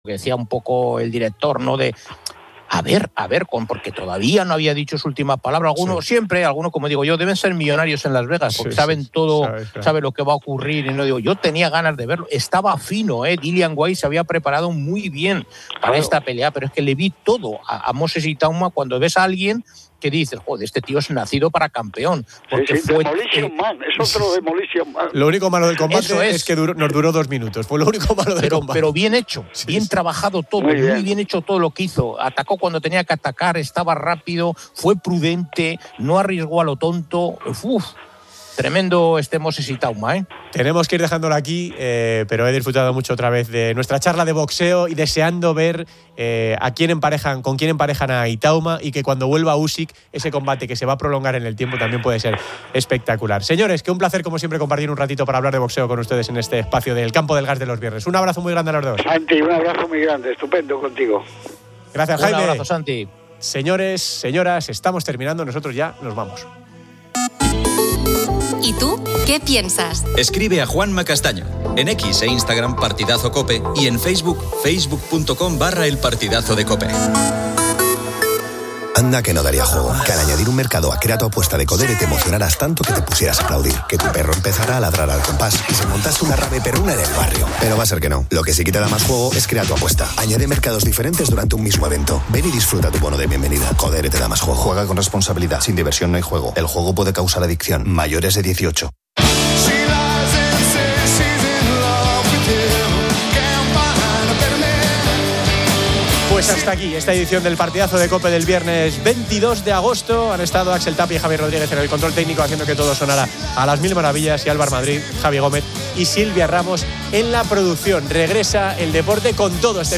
Posteriormente, el programa aborda la ceguera y los perros guía. Entrevistan a David Casinos, atleta paralímpico, quien comparte su vivencia al perder la vista hace 30 años.